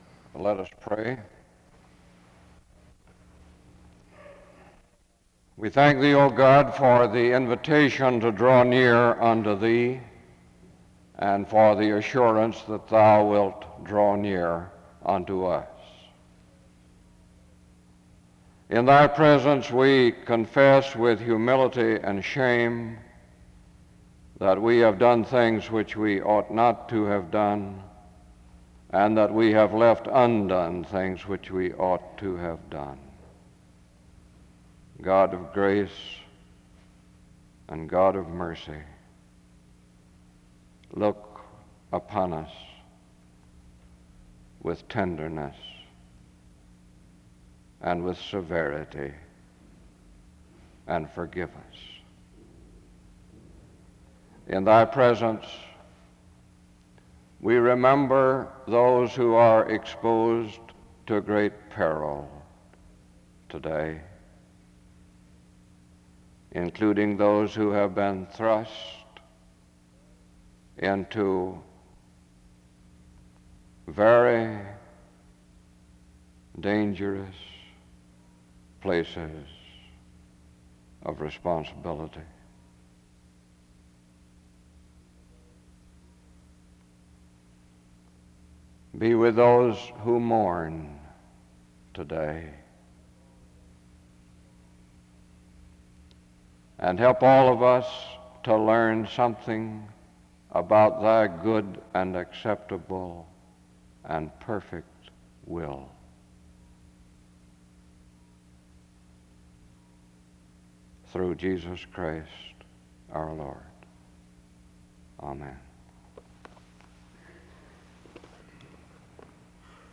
The service begins with a prayer (0:00-1:55).
There are then a few announcements given (1:56-4:25).
The service closes in prayer (24:03-24:36).